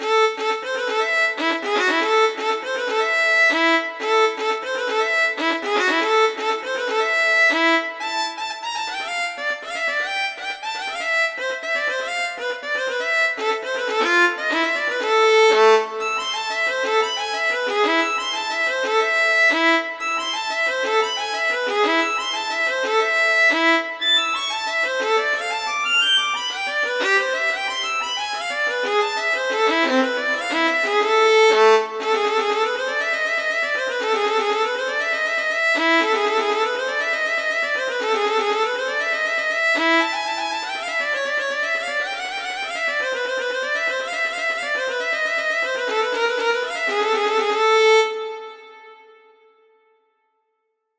capricho
música clásica